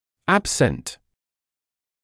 [‘æbsənt][‘эбсэнт]отсутствующий